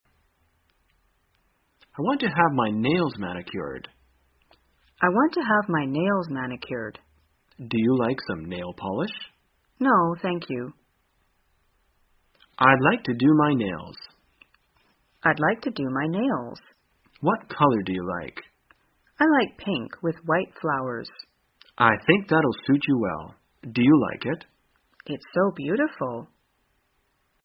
在线英语听力室生活口语天天说 第217期:怎样谈论美甲的听力文件下载,《生活口语天天说》栏目将日常生活中最常用到的口语句型进行收集和重点讲解。真人发音配字幕帮助英语爱好者们练习听力并进行口语跟读。